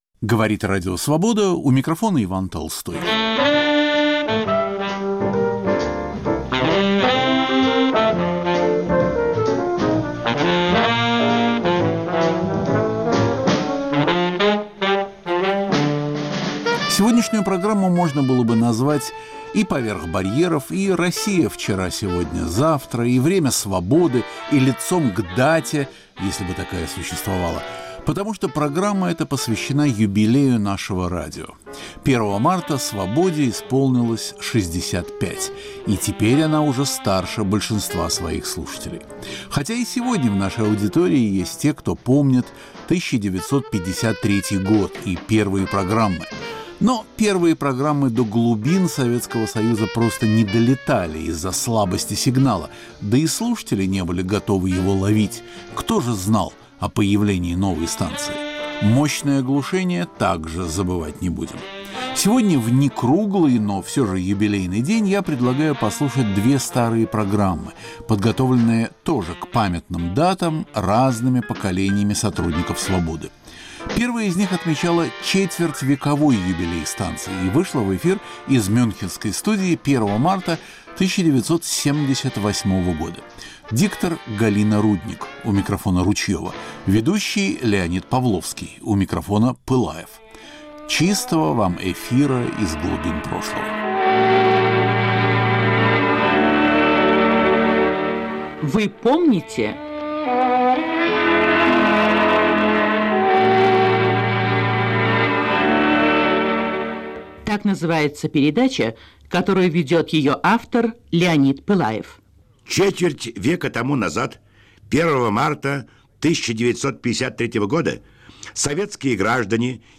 Ведущий Иван Толстой поднимает острые, часто болезненные, вопросы русского культурного процесса: верны ли устоявшиеся стереотипы, справедливы ли оценки, заслуженно ли вознесены и несправедливо ли забыты те или иные деятели культуры?